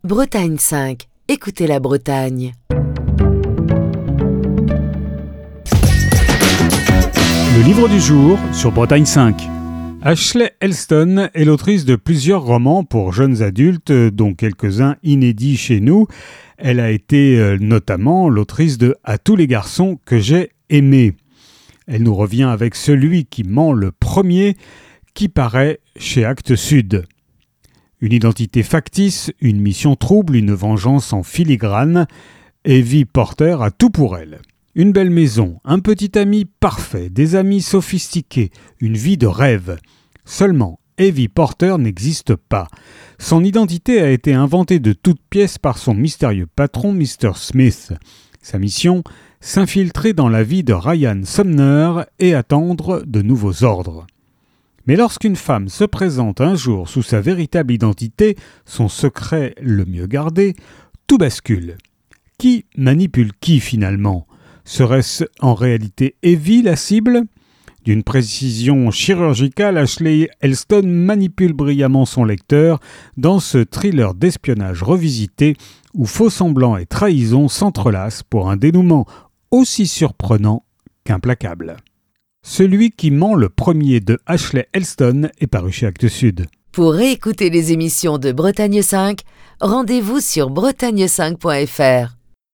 Chronique du 28 novembre 2025.